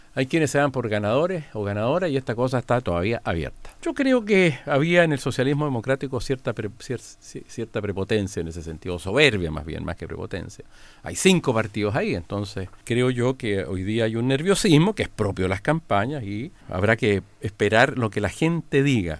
En conversación con Radio Bío Bío, afirmó que hay partidos que se dan por ganadores antes de las elecciones, señalando que existe un nerviosismo que es propio de las campañas.